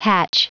Prononciation du mot hatch en anglais (fichier audio)